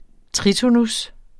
Udtale [ ˈtʁitonus ]